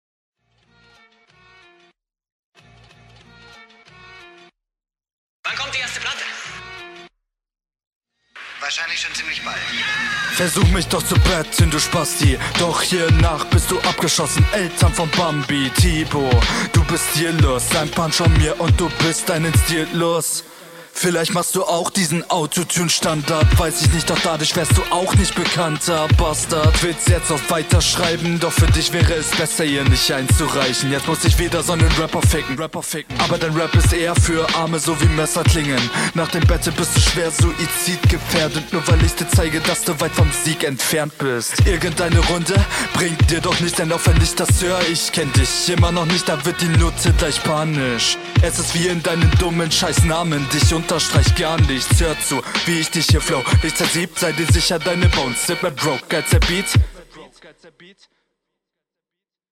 Flowlich ganz ok, nicht zuviel rumexperementiert aber in Ordnung.